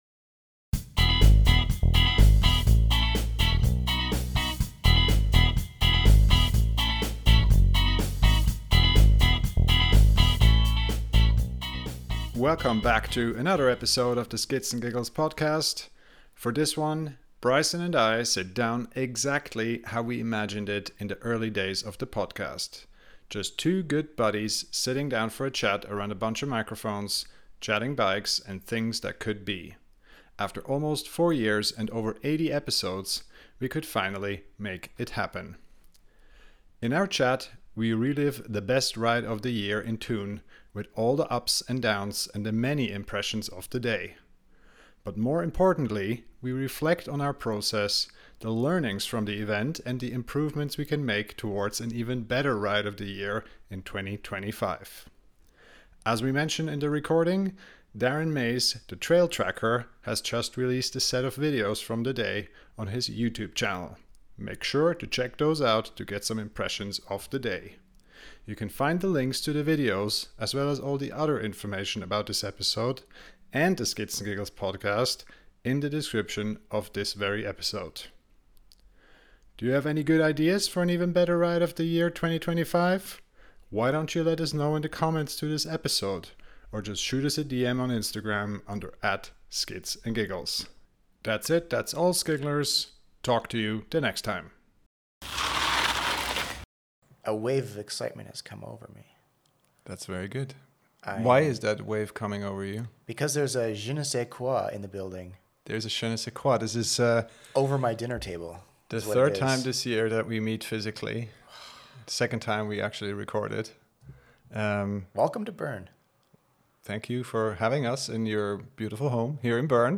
For this one, we sit down exactly how we imagined it in the early days of the podcast: Just two good buddies having a chat, talking about bikes and things